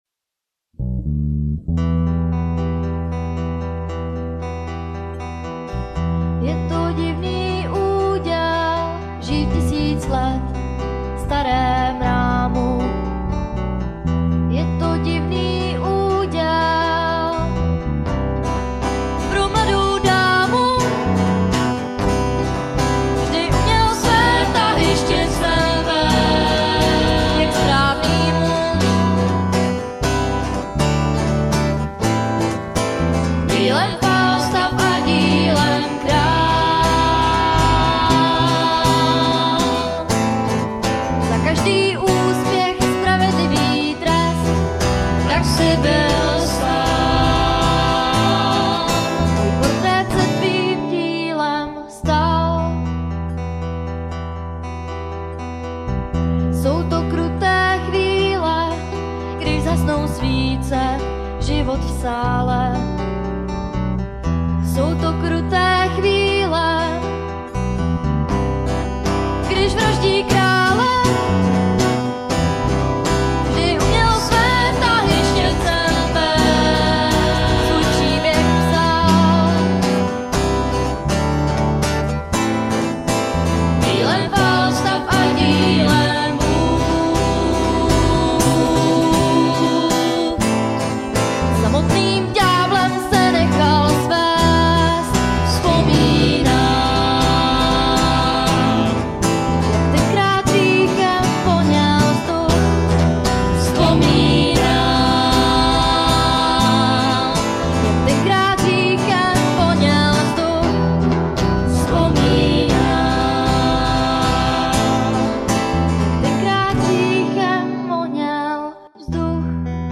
kategorie ostatní/písně
Barva hlasu zpěvačky je nádherná...
Je zpívaná čistě a něžně...